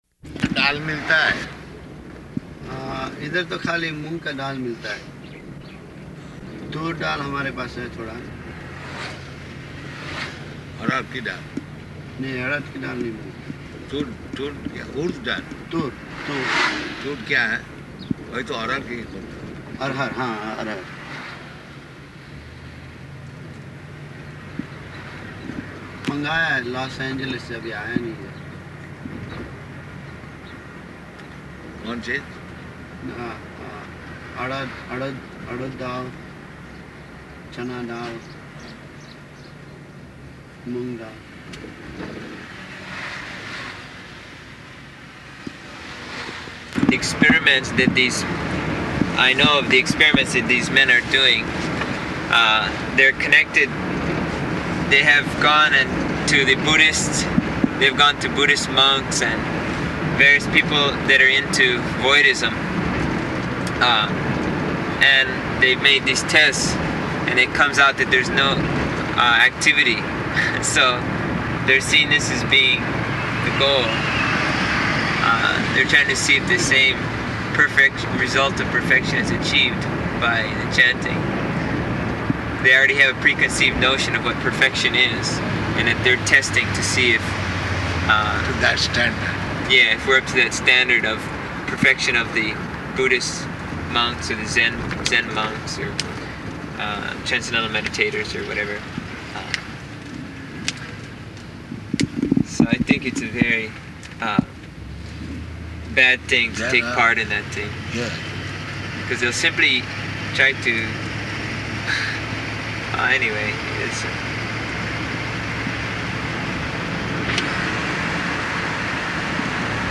Type: Walk
Location: Honolulu
[in car]